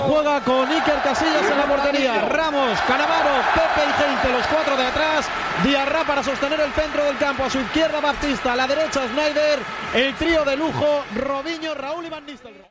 Narració del partit de la lliga masculina de primera divisió entre el Futbol Club Barcelona i el Real Madrid.
Esportiu